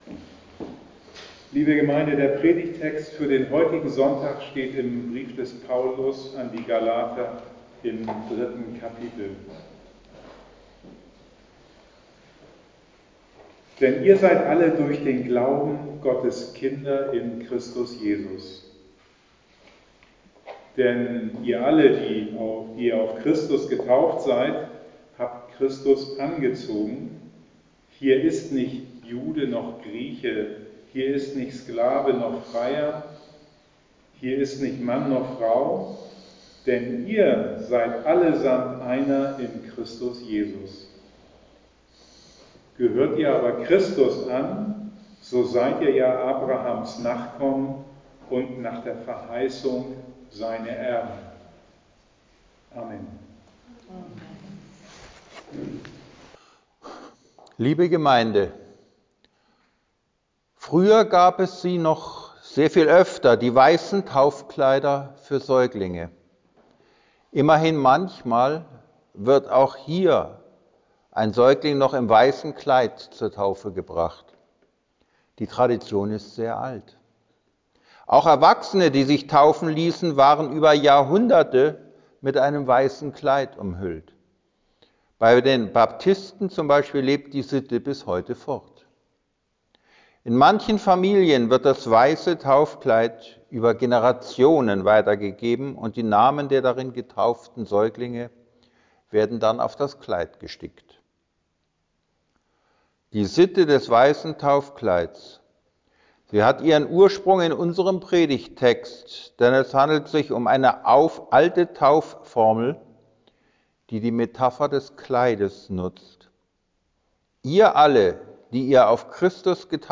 Predigten zum Anhören